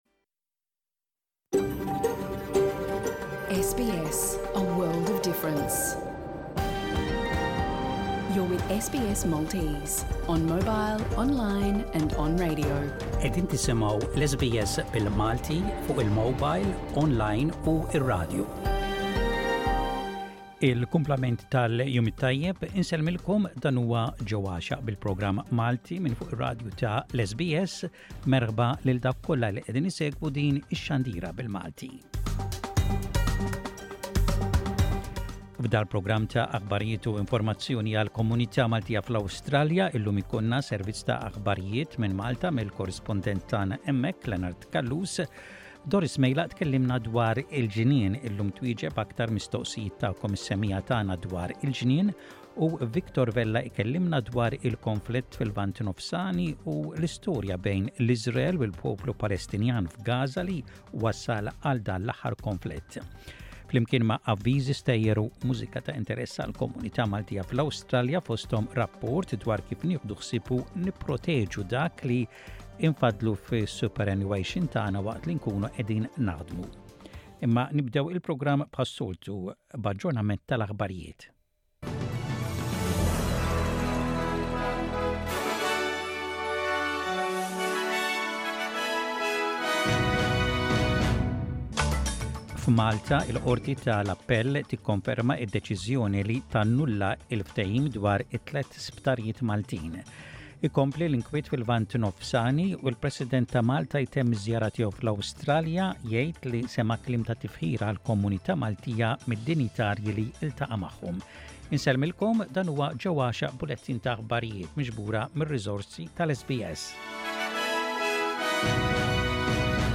SBS Radio | Aħbarijiet bil-Malti: 24.10.23